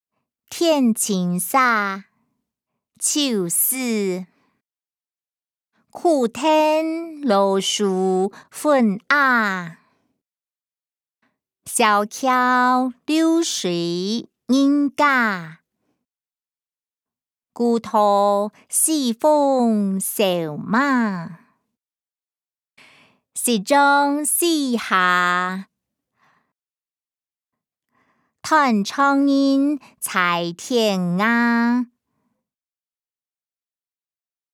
詞、曲-天淨沙：秋思音檔(海陸腔)